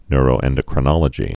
(nrō-ĕndə-krə-nŏlə-jē, nyr-)